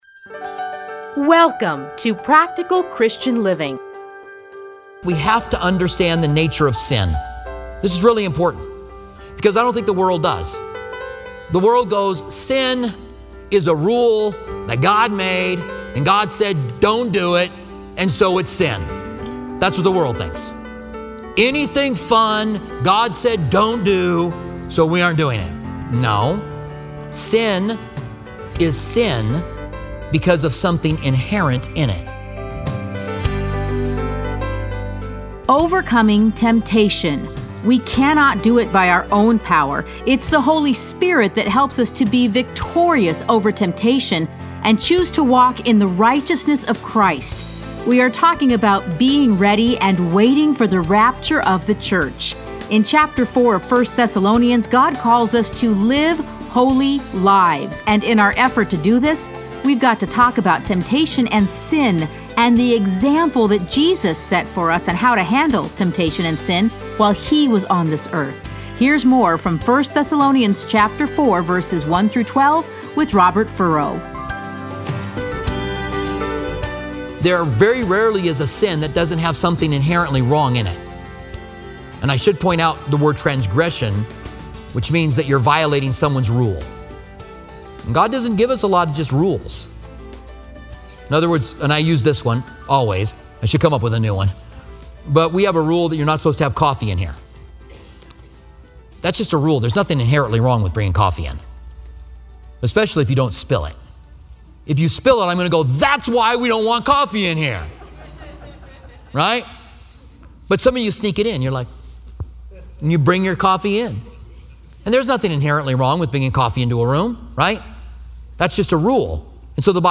Listen to a teaching from 1 Thessalonians 4:1-12.